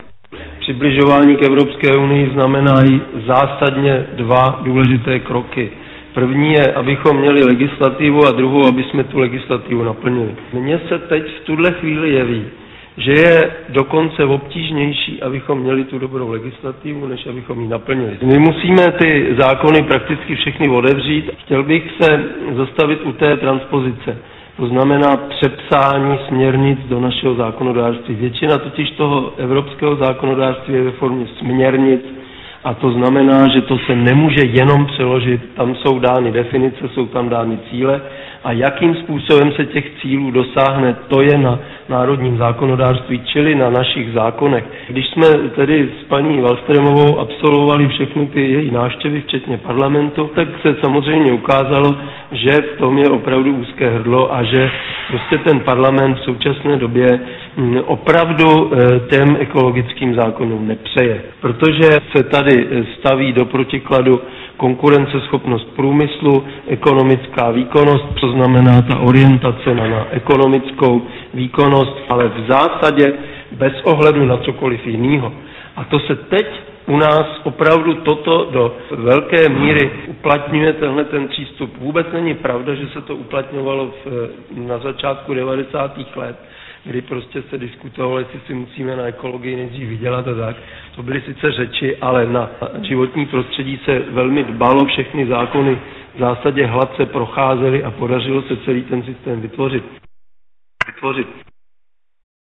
Na semináři v Akademii věd hovořil o podstatě přibližování České republiky k Evropské unii v oblasti životního prostředí prof. Bedřich Moldan, ředitel Centra pro otázky životního prostředí Univerzity Karlovy, zdůraznil tato fakta: